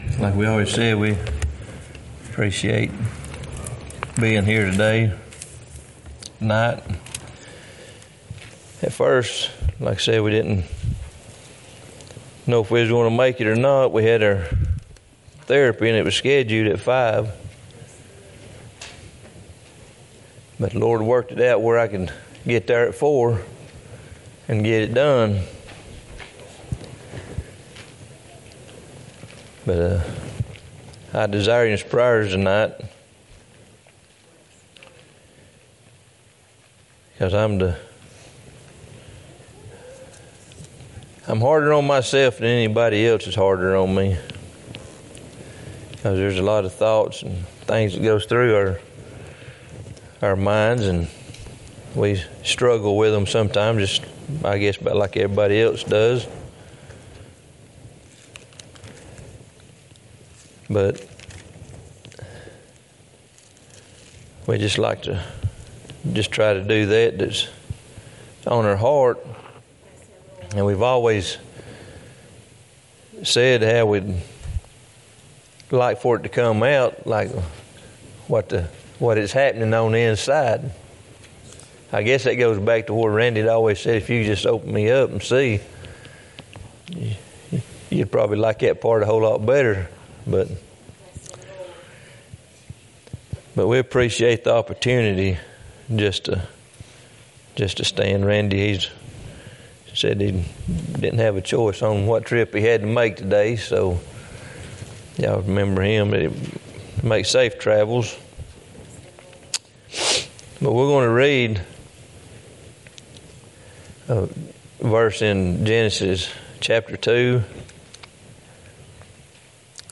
Genesis 2:8,3:22-24 Matthew 26:36 Service Type: Wednesday night Topics